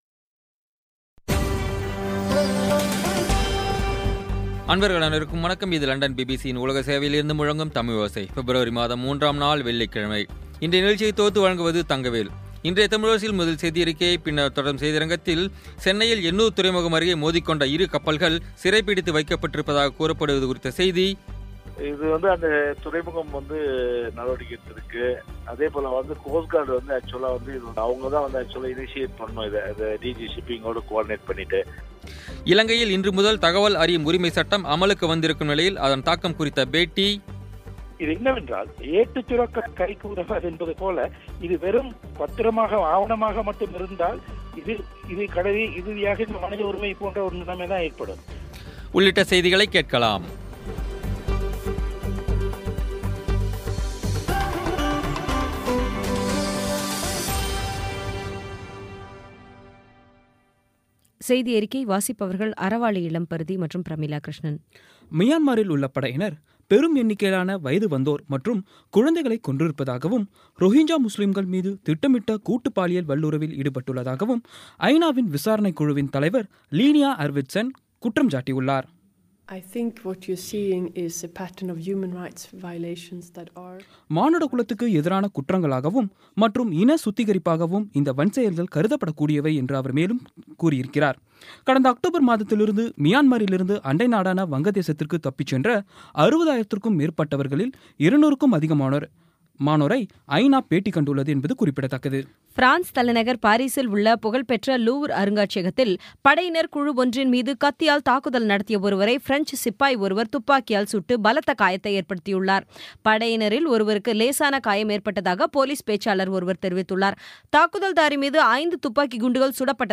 இன்றைய தமிழோசையில், முதலில் செய்தியறிக்கை, பின்னர் தொடரும் செய்தியரங்கத்தில், சென்னையில் எண்ணூர் துறைமுகம் அருகே மோதிக் கொண்ட இரு கப்பல்கள் சிறைபிடித்து வைக்கப்பட்டிருப்பதாகக் கூறப்படுவது குறித்த செய்தி இலங்கையில் இன்று முதல் தகவல் அறியும் உரிமை சட்டம் அமலுக்கு வந்திருக்கும் நிலையில் அதன் தாக்கம் குறித்த பேட்டி உள்ளிட்ட செய்திகளைக் கேட்கலாம்